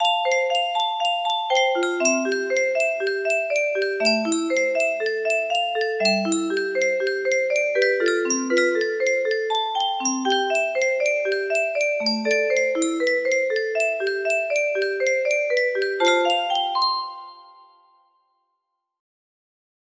Bigbang 2 music box melody